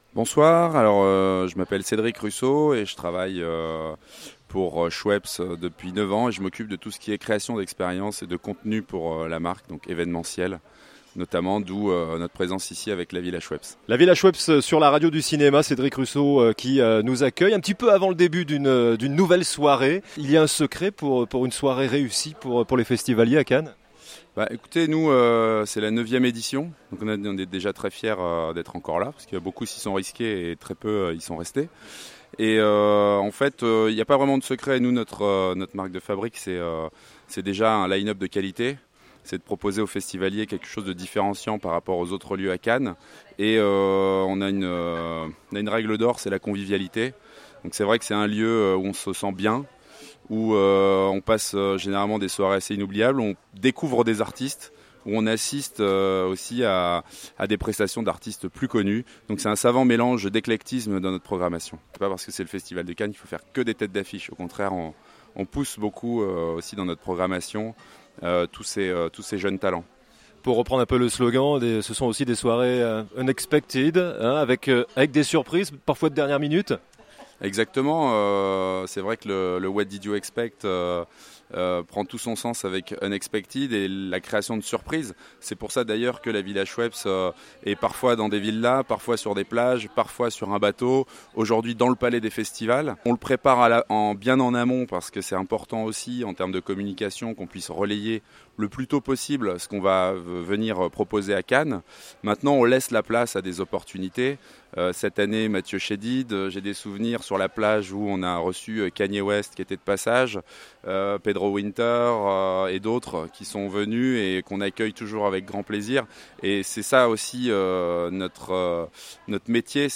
L'interview pour la VILLA SCHWEPPES